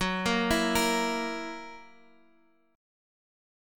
Gb7 chord